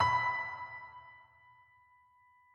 multiplayerpiano - An online piano you can play alone or with others in real-time.
b4.mp3